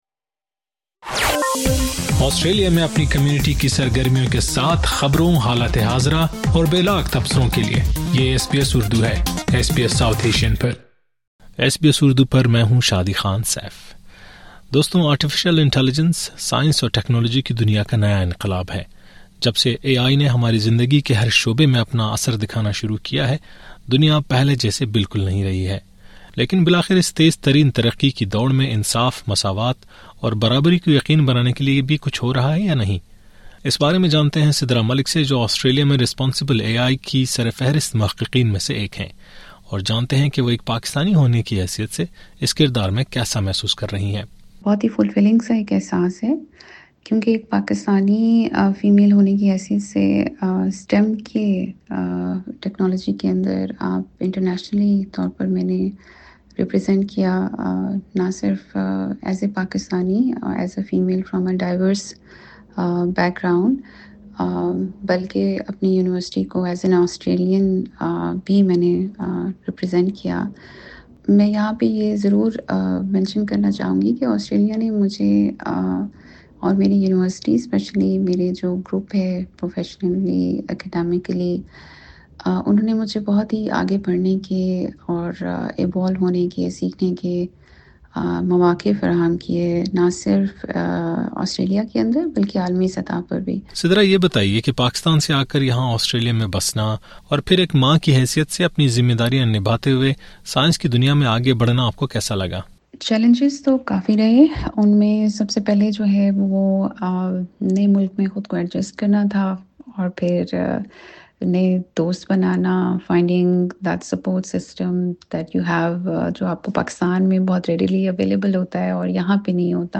SBS اردو سے بات چیت